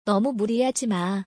ノム ムリハジマ